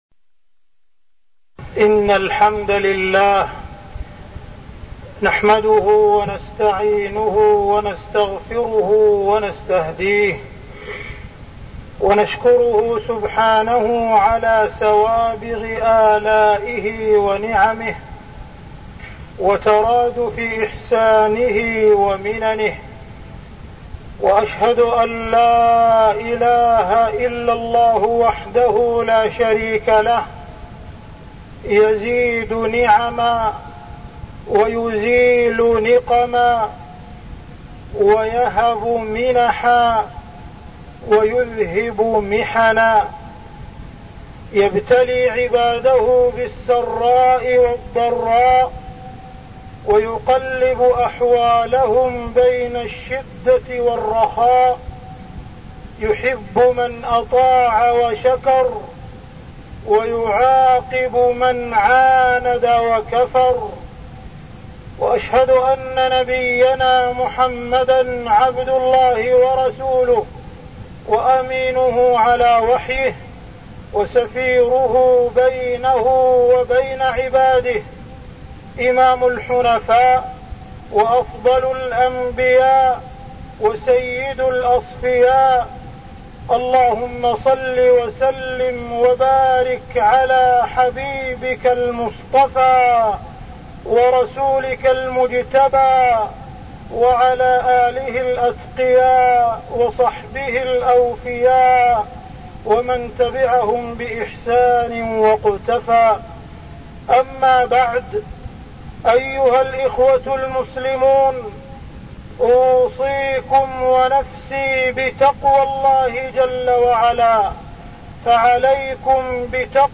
تاريخ النشر ٤ شعبان ١٤١٢ هـ المكان: المسجد الحرام الشيخ: معالي الشيخ أ.د. عبدالرحمن بن عبدالعزيز السديس معالي الشيخ أ.د. عبدالرحمن بن عبدالعزيز السديس دين الإسلام